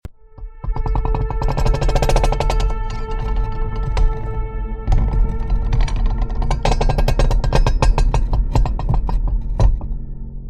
SOUND DESIGN 🎧🔊⚙ For Movies Sound Effects Free Download